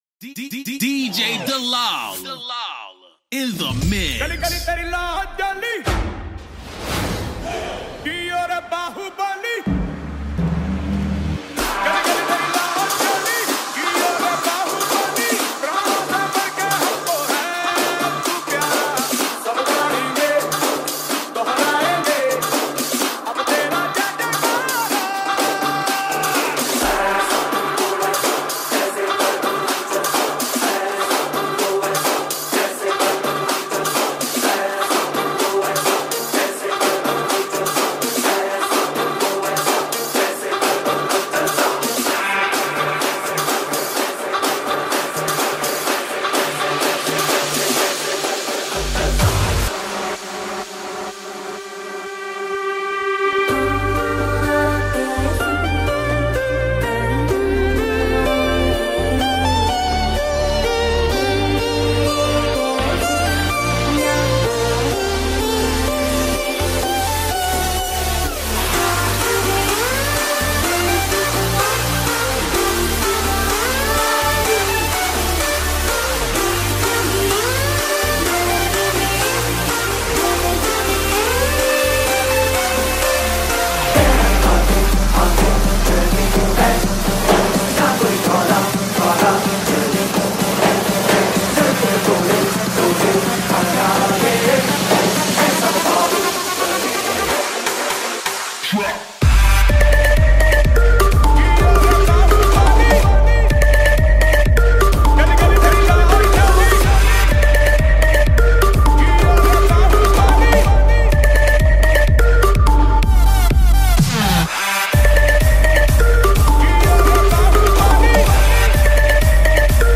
Single DJ Remix Songs